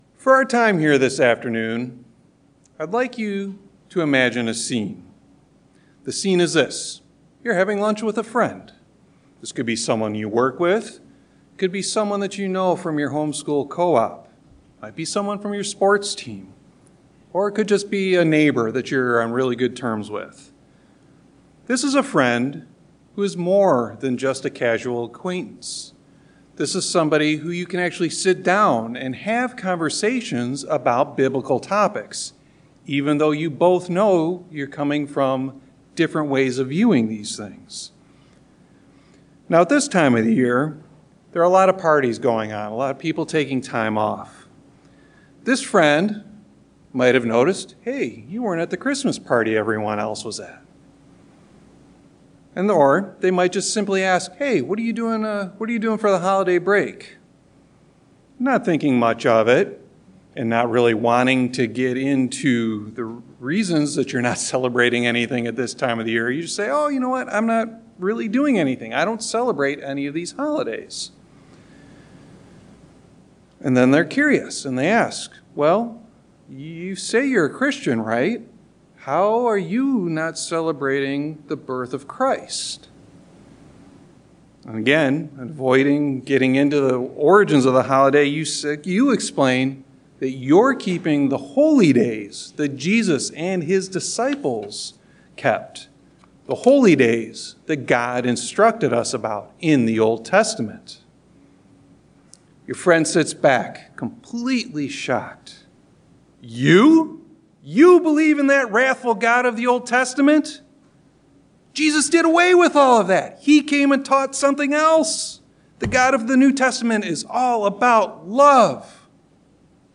In this message, we'll review God's unchanging nature through paired Old Testament and New Testament scriptures. Additionally, we'll review why we understand the "God of the Old Testament" to be a pre-incarnate Jesus Christ, as well as why this truth of God's unchanging nature MUST be foundational to our understanding and our faith.